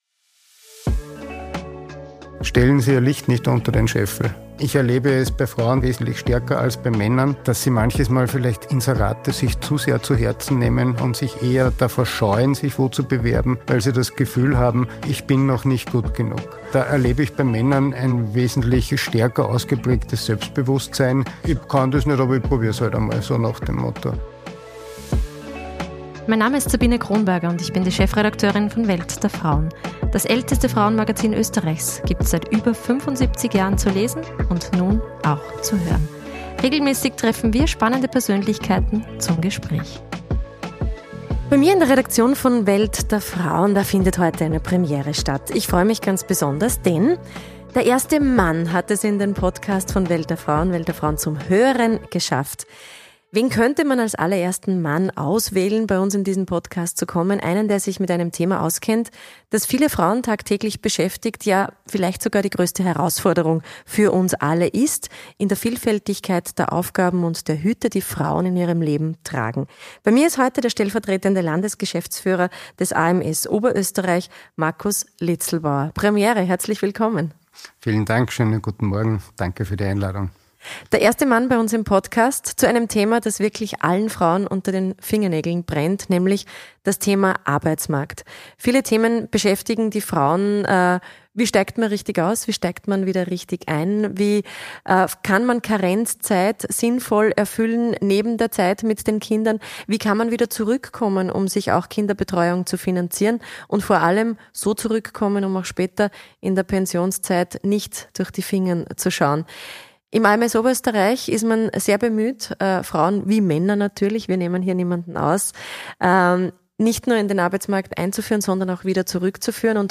Ein Gespräch über Gleichstellung, Integration und die Zukunft des Arbeitsmarktes.